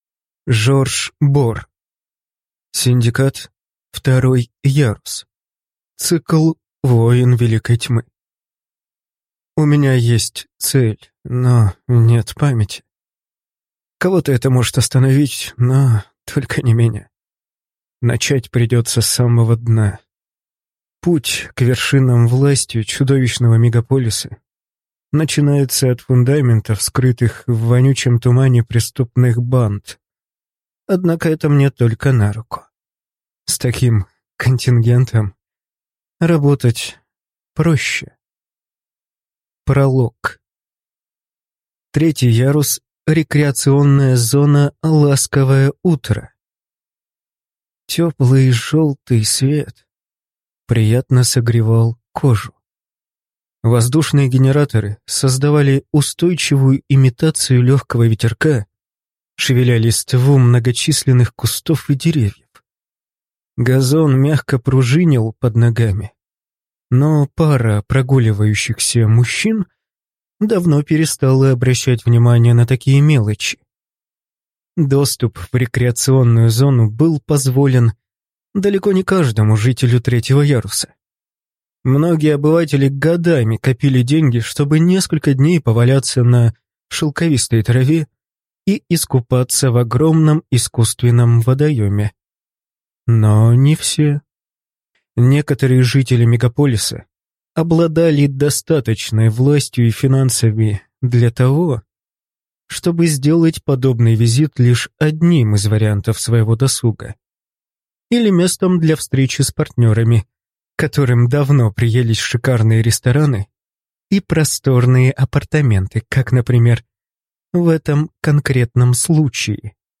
Аудиокнига Синдикат. Синдикат. Второй ярус | Библиотека аудиокниг